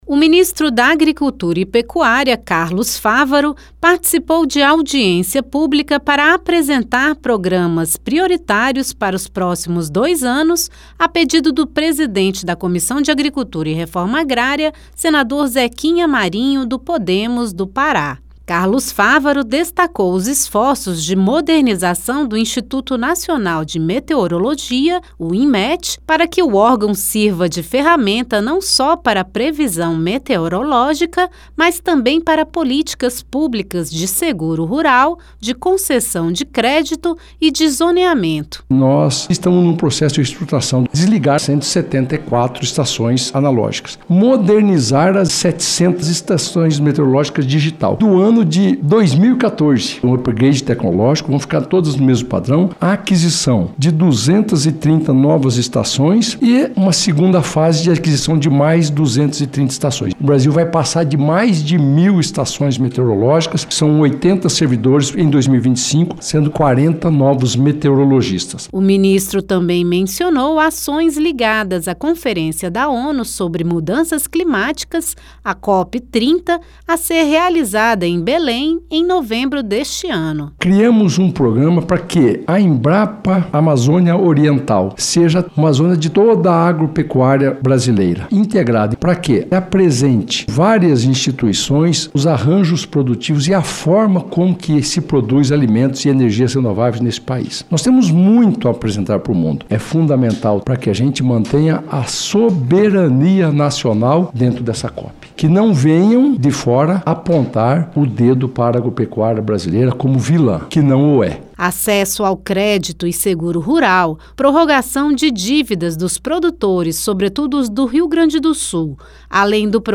Senador licenciado Carlos Fávaro veio ao Senado para falar sobre as principais ações do Ministério da Agricultura e Pecuária. Convidado pelo presidente da Comissão de Agricultura e Reforma Agrária, senador Zequinha Marinho (Podemos-PA), o ministro ressaltou a reestruturação do Instituto Nacional de Meteorologia (Inmet) e a oportunidade de mostrar feitos da agropecuária brasileira na COP30, que acontece em Belém, em novembro deste ano.